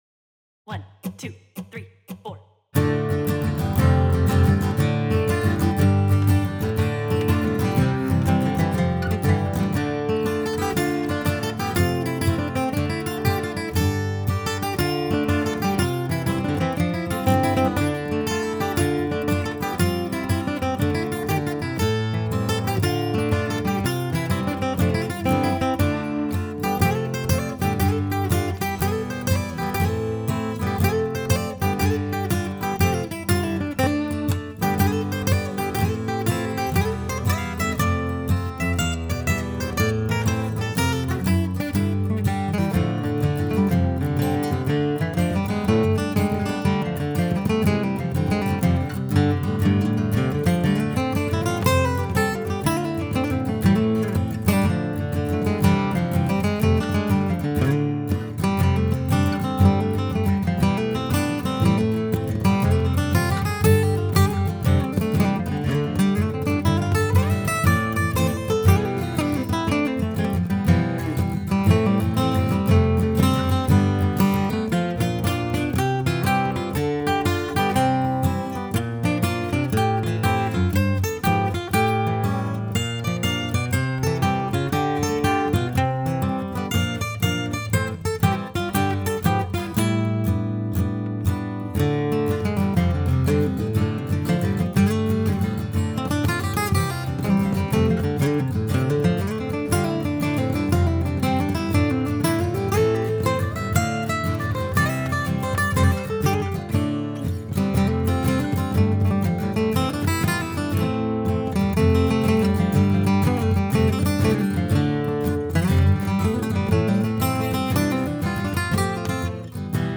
Studio Tunesmith Studio
Notes Traditional Irish jig. Recorded during a session in Nashville.
guitar
trading leads on the melody.